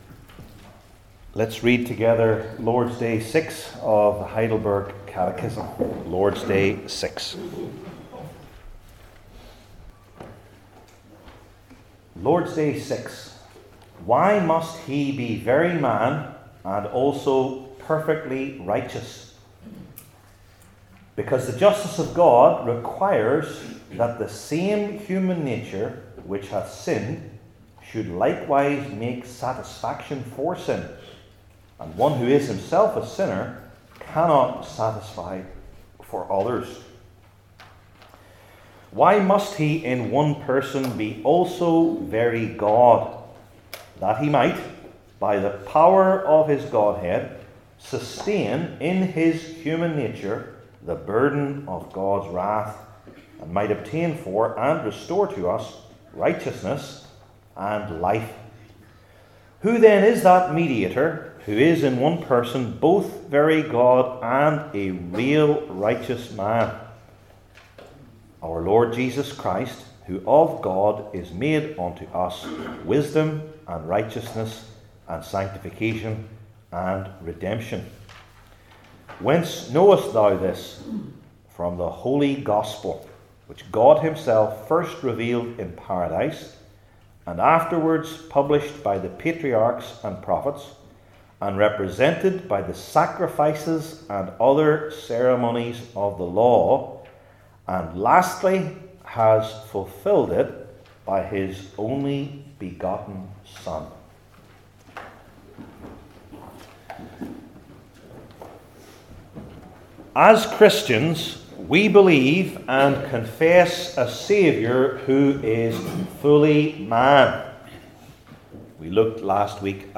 Heidelberg Catechism Sermons I. The Wrath of God II.